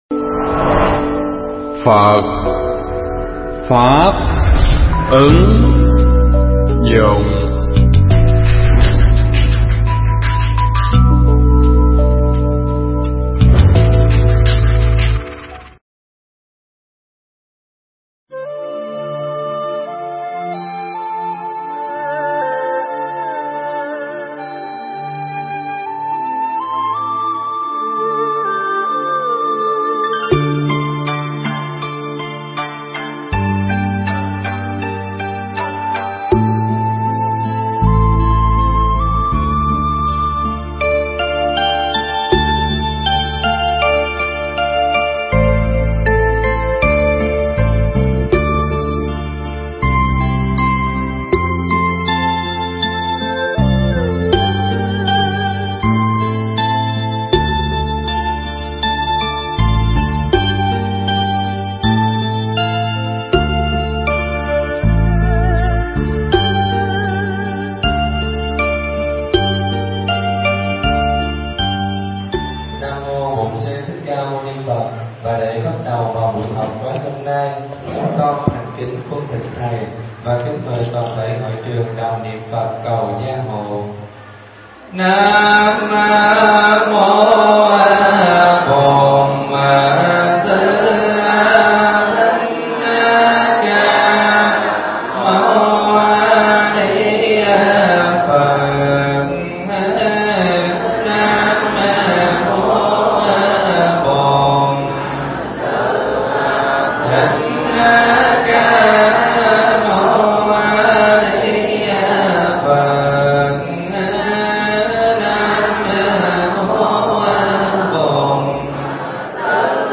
Mp3 pháp thoại Kinh Pháp Cú Phẩm Ngàn